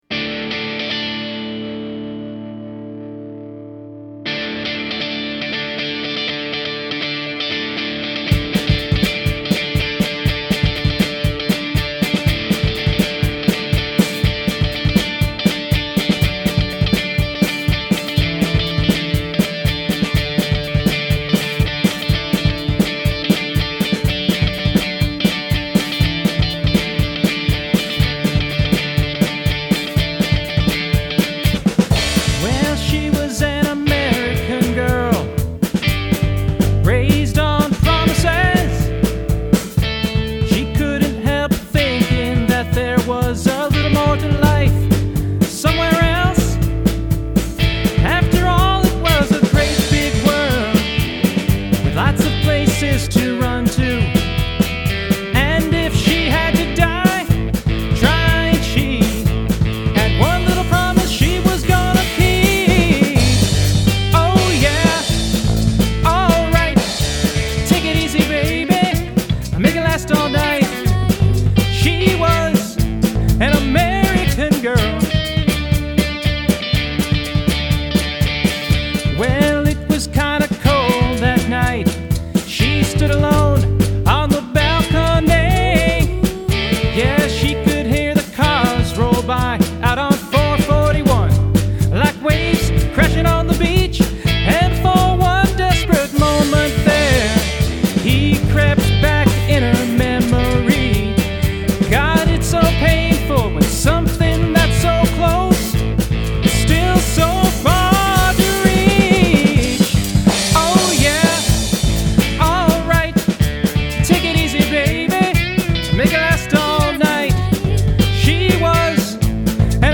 Just Some Demos We Recorded in Our Basement
drums, percussion
keyboards, saxophone, vocals